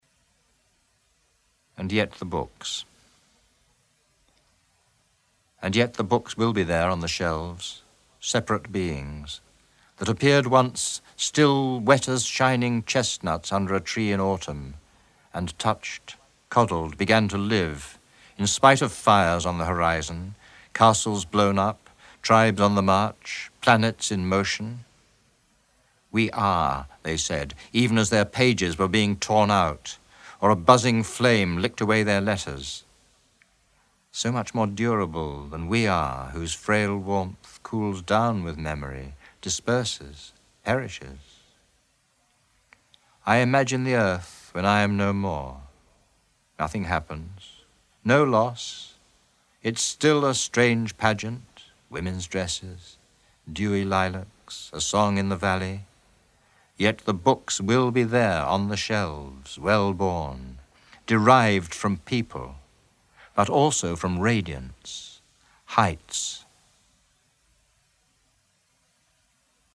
The Poet Speaks from Poems on the Underground Audiobook 1994